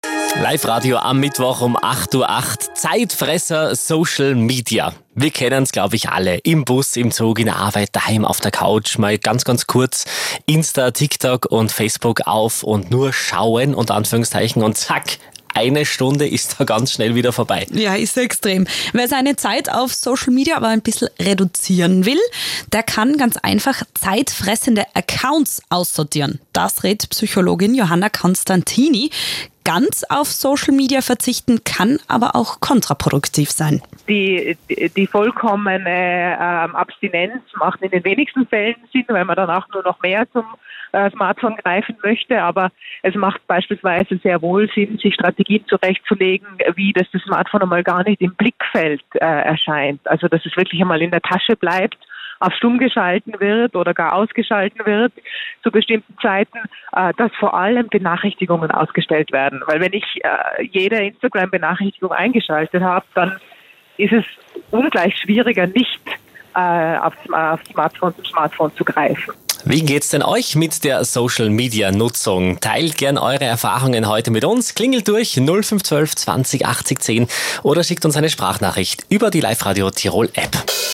Social Media als Allzeitbegleiter – im Life Radio Interview